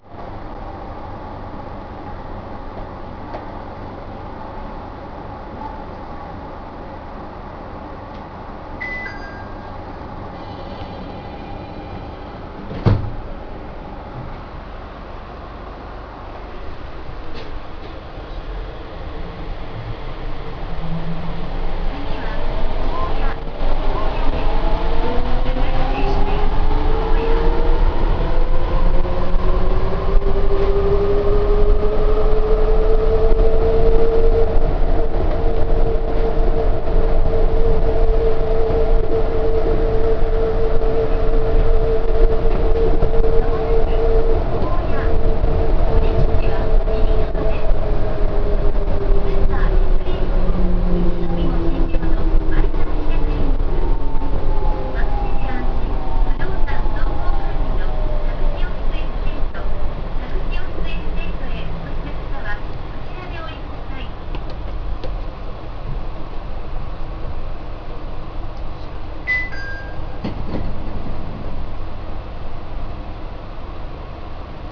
・330形走行音
【日暮里・舎人ライナー】扇大橋〜高野（1分14秒：407KB）
走行装置自体は300形以来変更はありません。